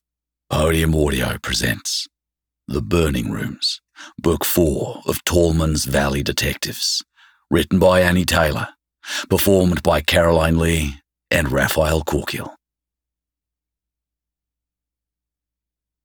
opening credits